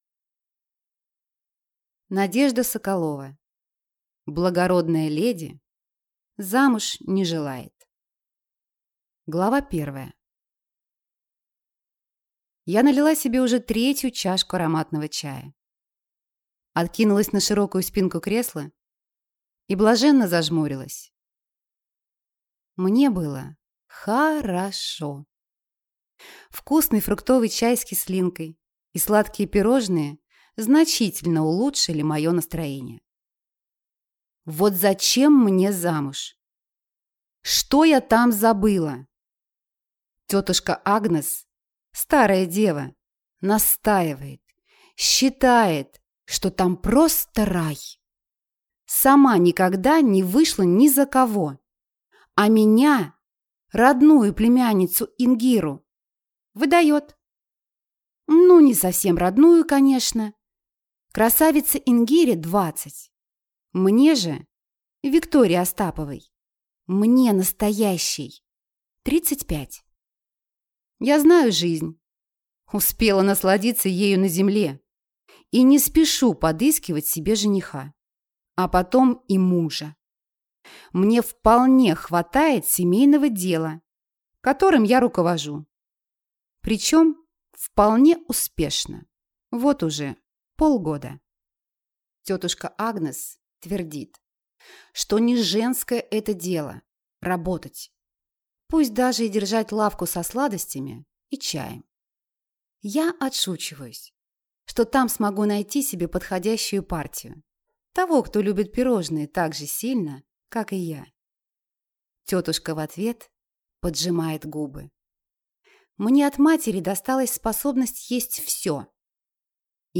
Аудиокнига Благородная леди замуж не желает | Библиотека аудиокниг
Прослушать и бесплатно скачать фрагмент аудиокниги